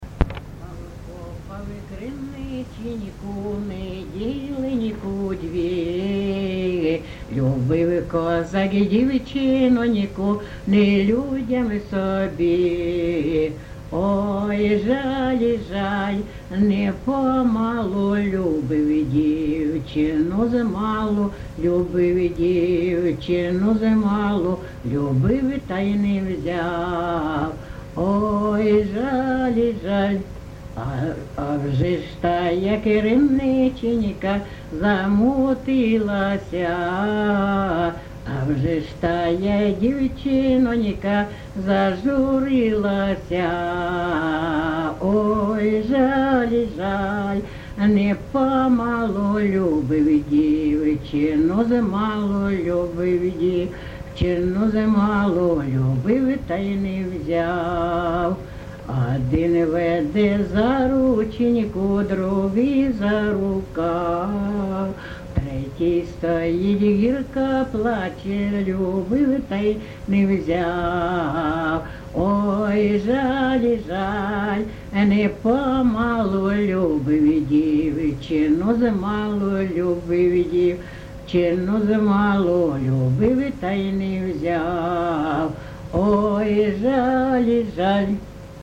ЖанрПісні з особистого та родинного життя
Місце записус. Некременне, Олександрівський (Краматорський) район, Донецька обл., Україна, Слобожанщина